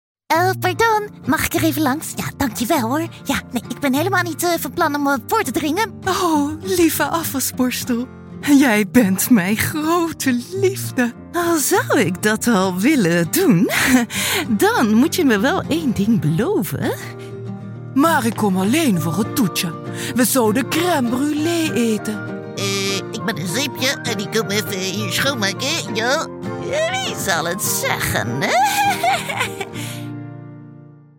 Naturelle, Enjouée, Accessible, Polyvalente, Amicale